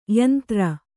♪ yantra